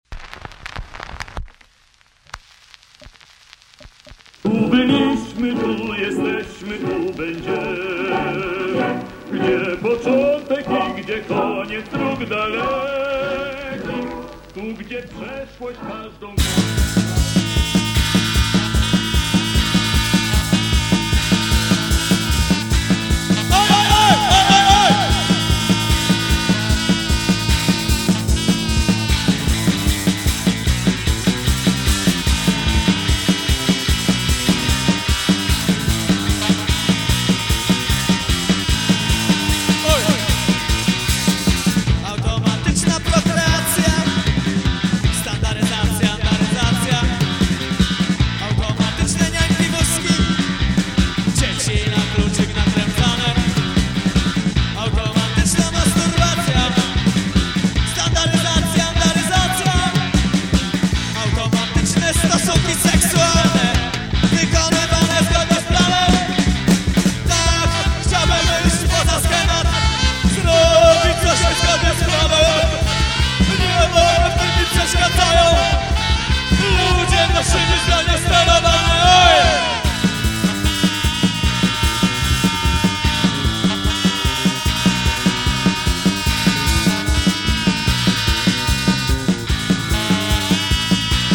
Wiekszosc jest nawet nagrana w stereo.
Punk laczony byl z innymi gatunkami muzycznymi.